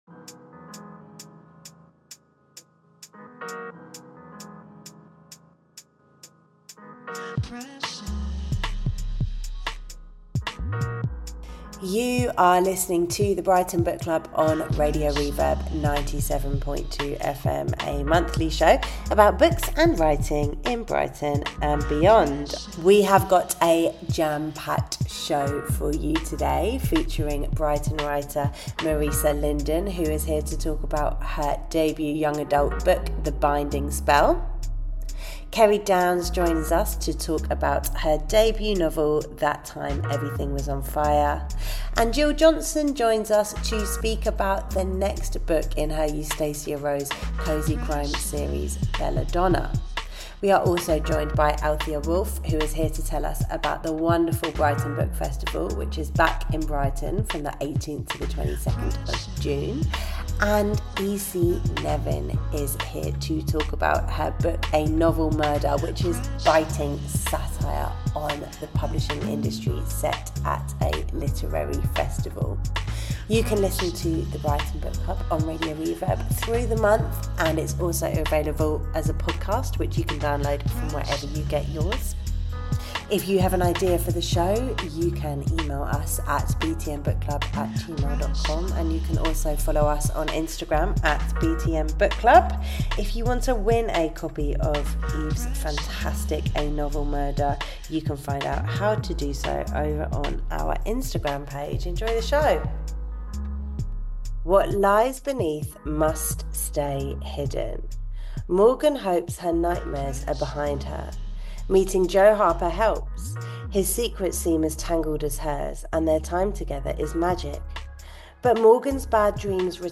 interviews authors, publishers, people working in literature programmes, gets recommendations from booksellers and bloggers